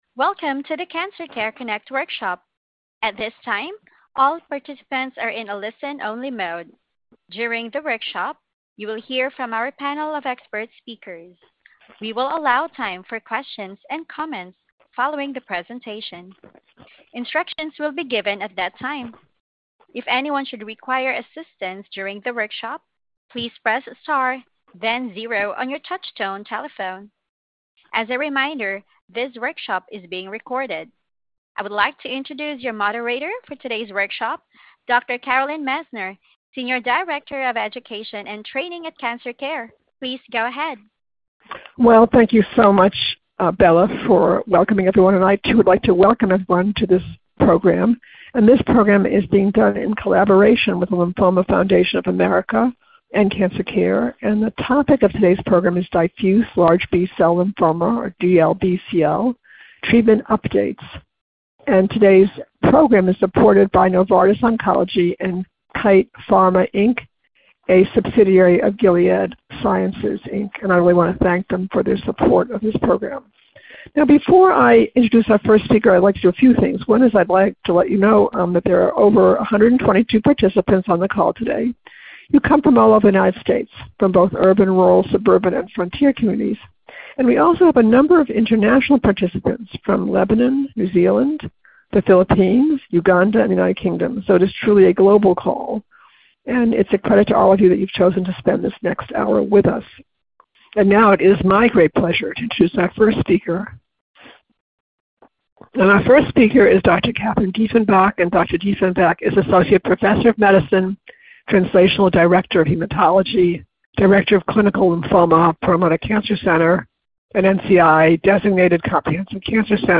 Questions to Ask Our Panel of Experts